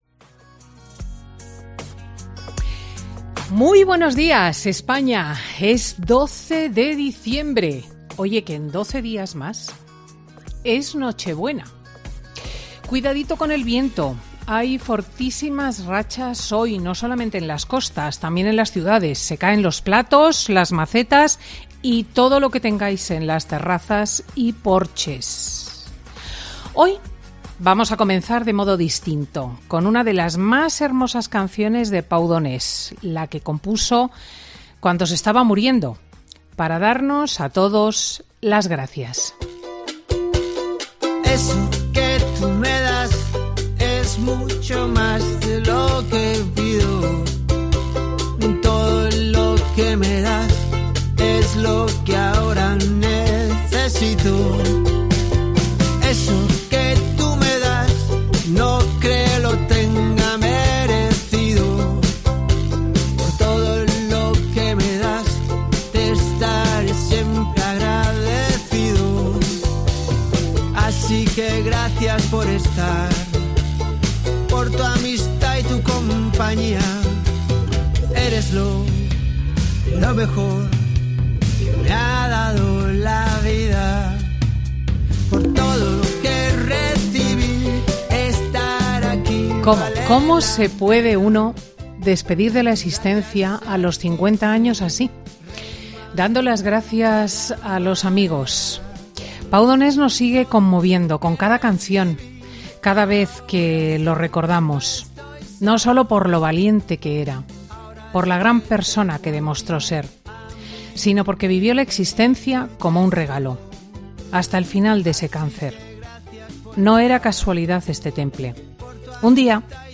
AUDIO: Cristina López Schichting repasa la actualidad de la jornada en su editorial de este sábado, 12 de diciembre
Monólogo de Cristina López Schlichting